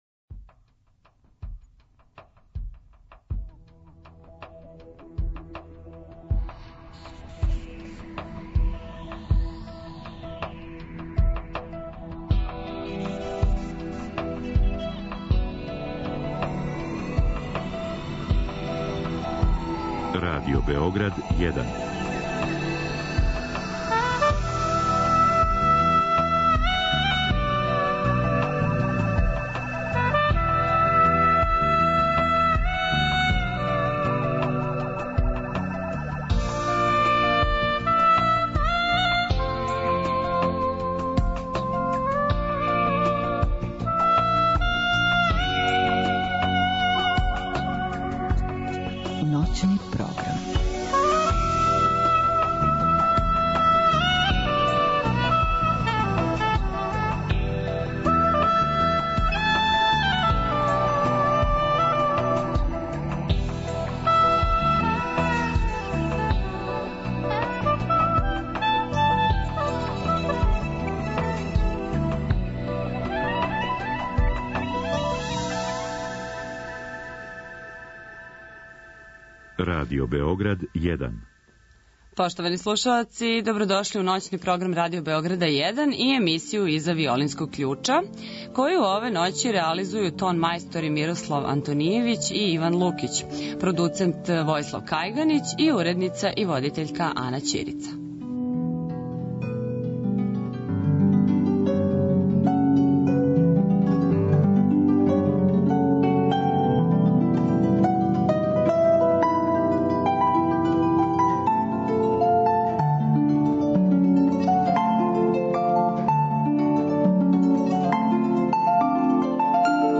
У другом делу емисије поред осталог имаћете прилику да чујете и одабране снимке са традиционалног Новогодишњег концерта који је 1. јануара одржан у Златној дворани Бечког музичког друштва (Musikverein), када је оркестром Бечке филхармоније дириговао славни Даниел Баренбоим.